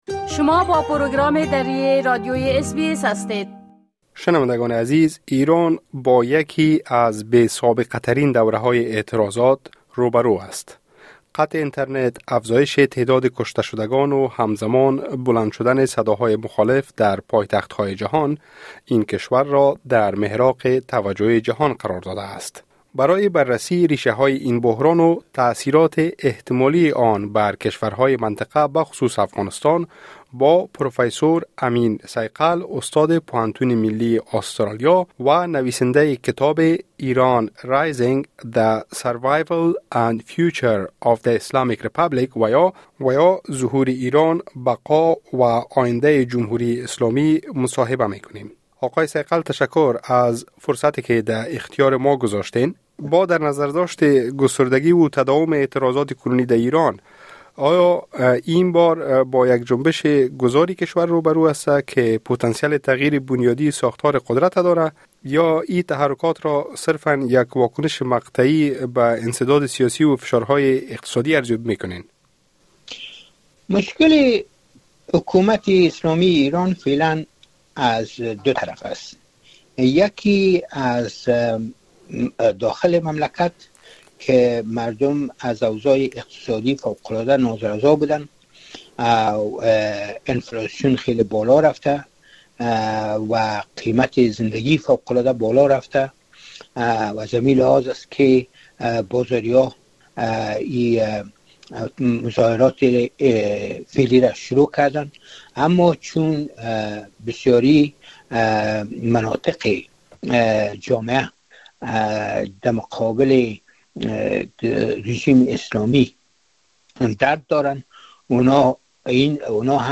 به گفته او، در صورت سقوط رژیم اسلامی، ایران به احتمال زیاد دچار تشنج داخلی نیز خواهد شد. گفت‌وگوی کامل اس‌بی‌اس دری